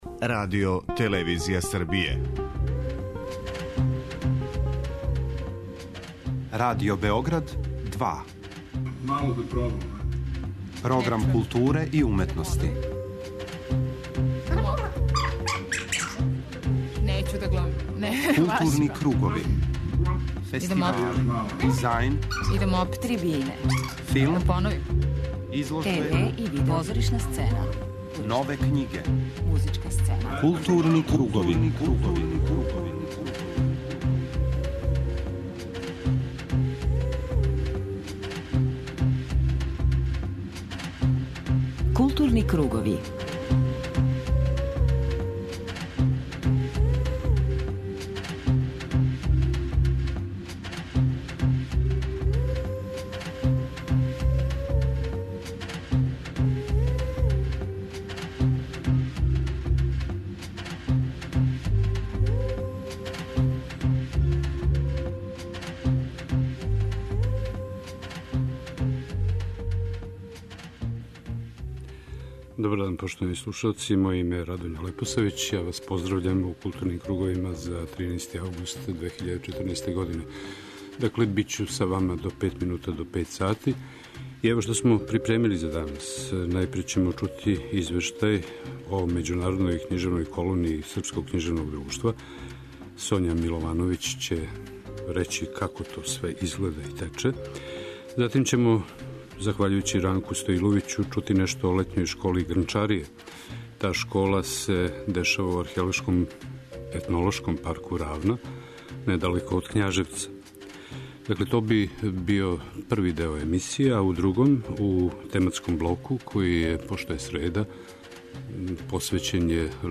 Емитоваћемо разговор који је 2007. године снимљен управо у једној од париских кафаница